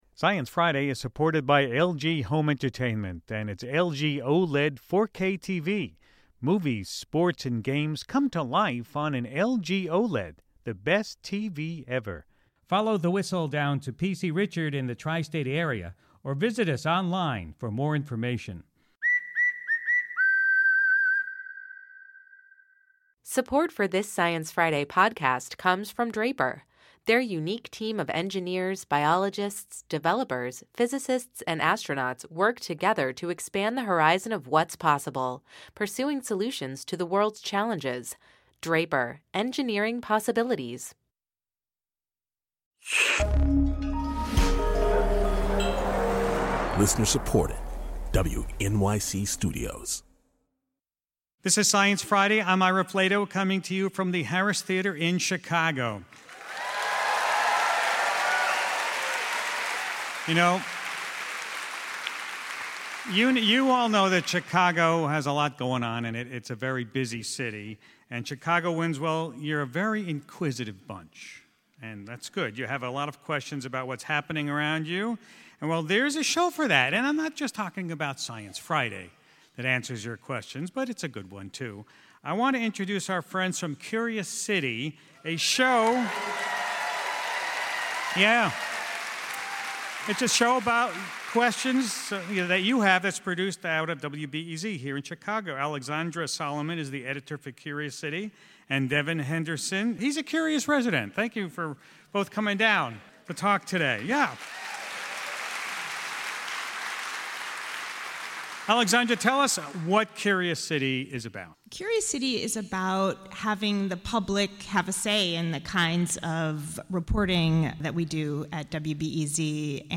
At our live event at the Harris Theater in Chicago, we called on WBEZ’s Curious City to help us out.